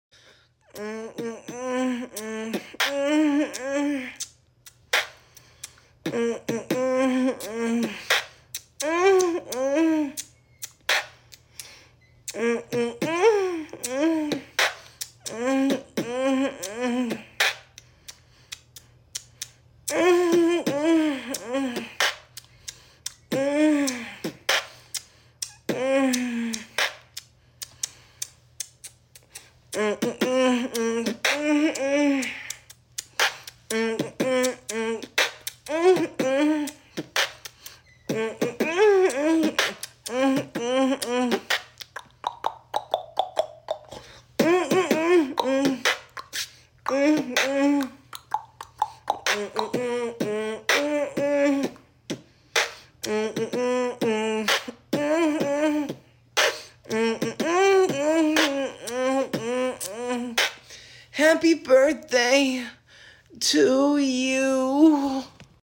Happy Birthday Moan Beatbox
u3-Happy-Birthday-Moan-Beatbox.mp3